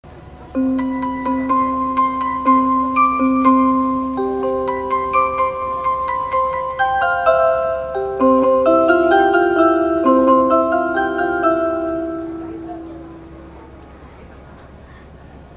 沖縄都市モノレール（ゆいレール） 車内チャイム
沖縄のわらべうたや民謡をアレンジした車内チャイムが流れます。
okinawa-oroku.mp3